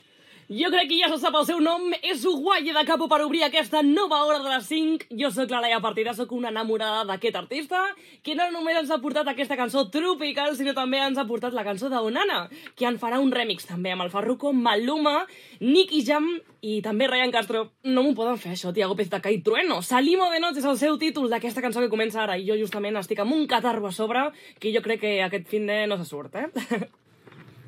Presentació d'un tema musical